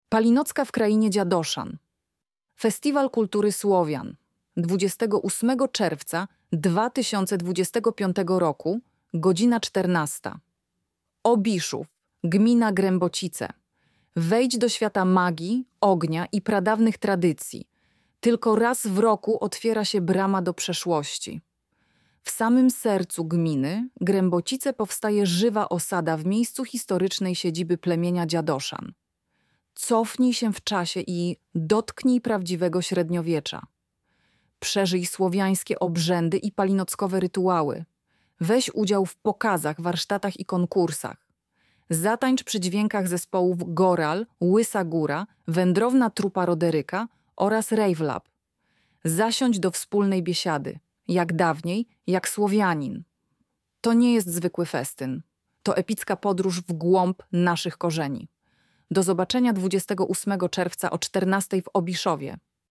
Palinocka-w-Krainie-Dziadoszan-lektor.mp3